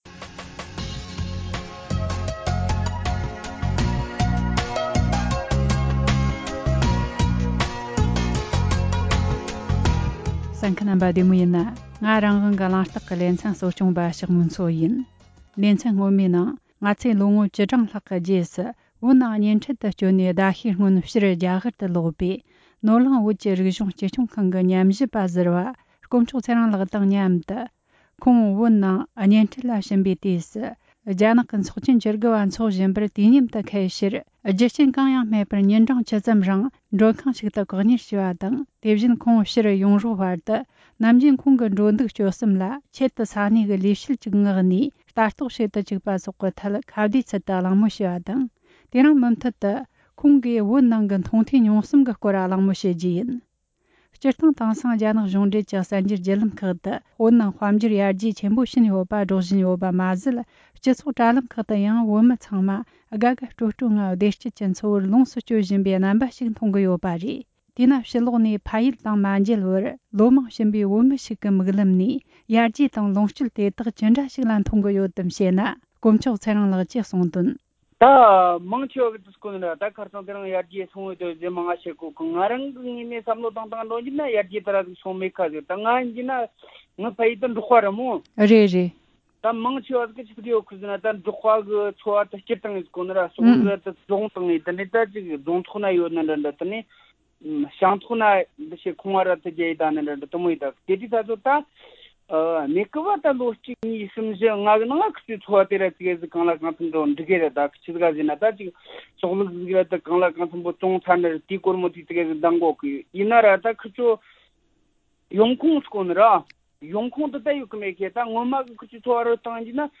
བོད་ནང་གཉེན་འཕྲད་དུ་ཕྱིན་པའི་བོད་མི་ཞིག་དང་མཉམ་དུ་བོད་ནང་གི་འགྱུར་བ་དང་བོད་མིའི་འཚོ་གནས་སོགས་ཀྱི་སྐོར་ངོ་སྤྲོད་གནང་བ།